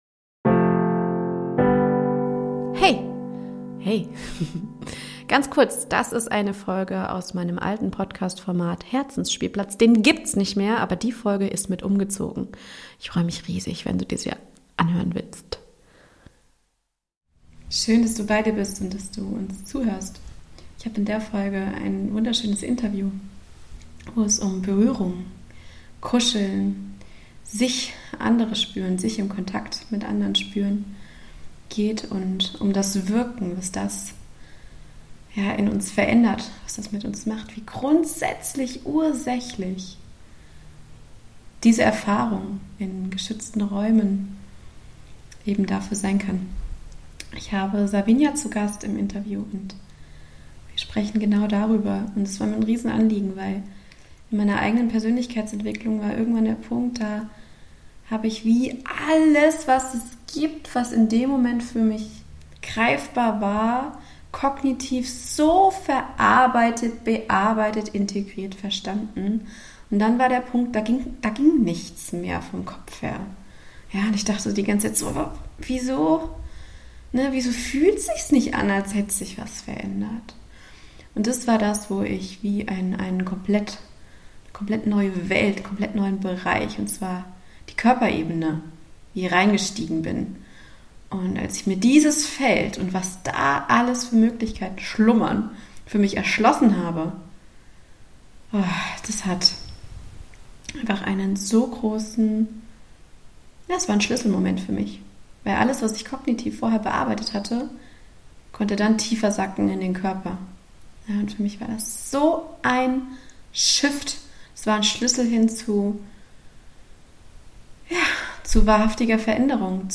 Interview: Das heilsame Feld der Berührung - Kuscheln und Körperglück ~ Herzens Spielplatz Podcast